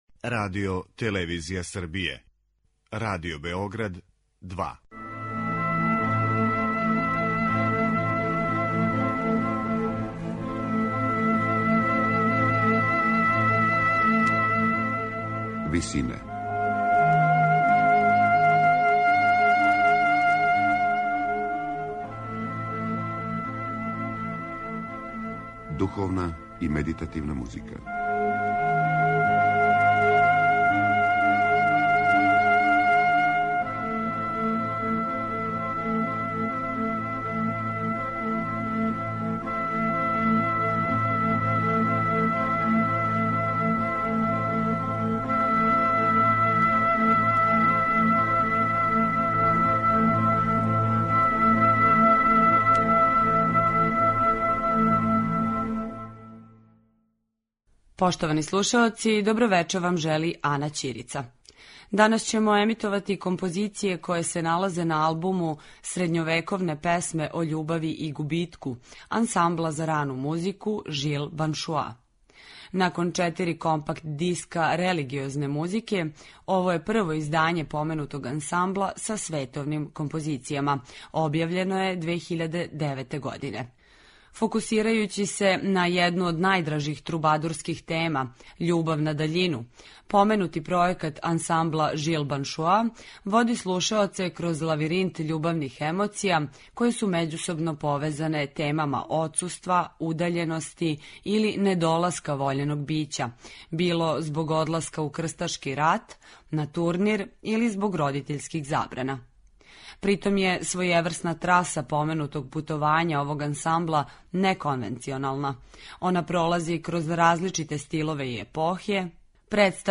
Средњовековне песме о љубави и губитку
Фокусирајући се на једну од најдражих трубадурских тема - љубав на даљину, ансамбл "Жил Баншуа" води слушаоце кроз лавиринт емоција које су међусобно повезане темама одсуства, удаљености или недоласка вољеног бића...